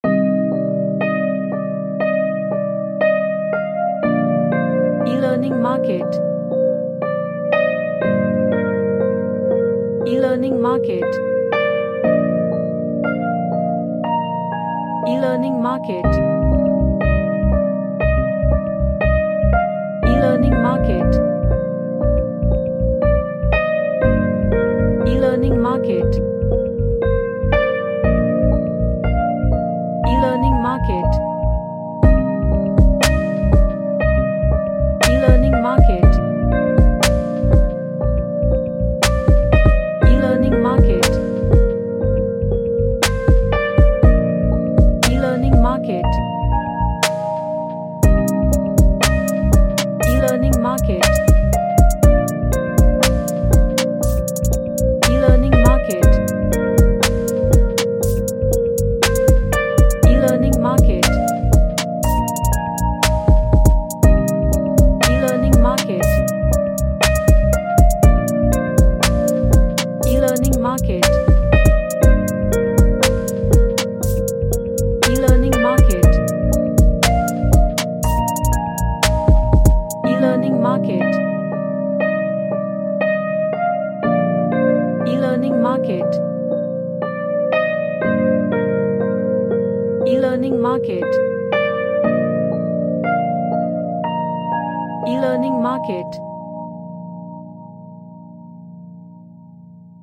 A chill sounding R&B track
Chill Out